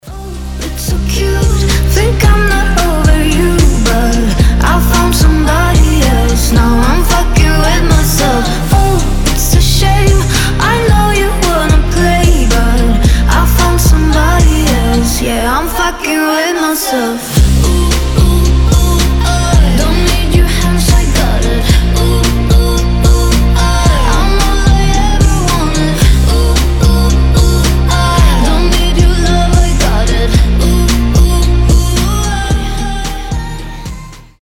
• Качество: 320, Stereo
женский голос
озорные